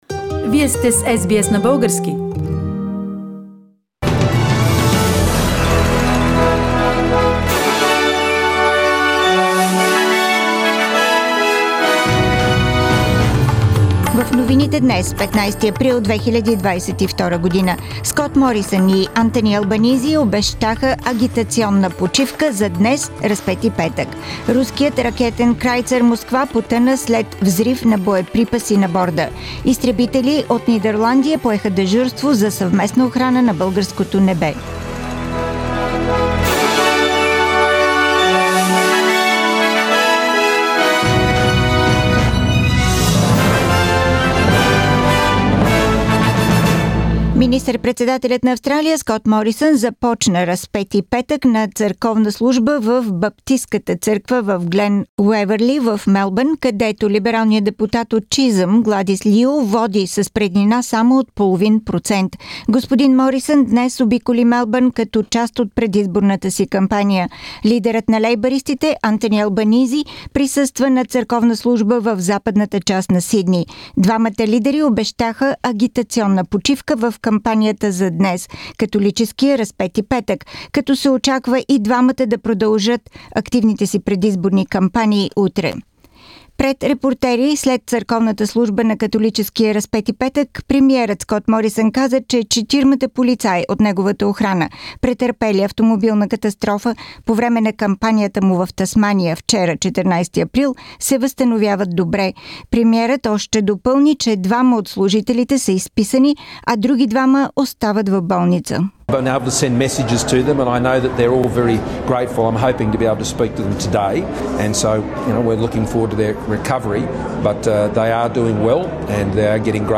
Радио SBS новини на български език - 15-ти април 2022